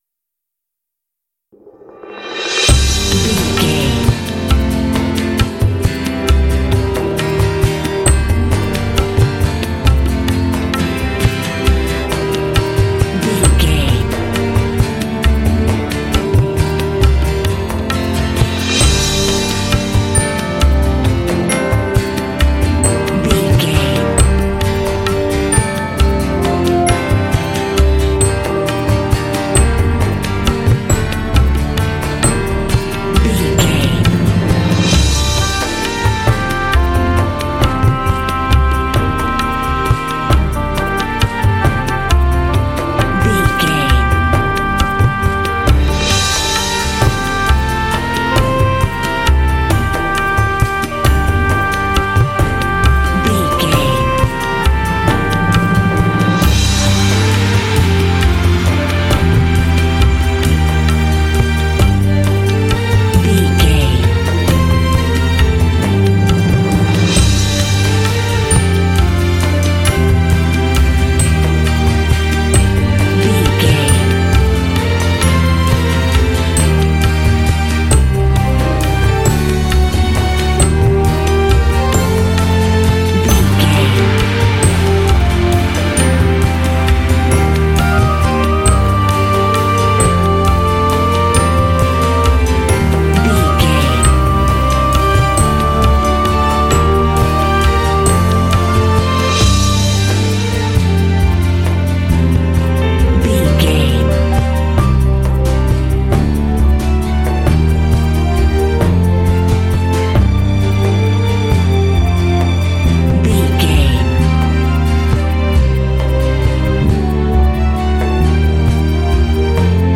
Uplifting
Aeolian/Minor
Slow
mystical
dreamy
peaceful
percussion
acoustic guitar
strings
bass guitar
drums
horns
oboe
cinematic